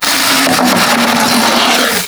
MONSTER_Noise_04_mono.wav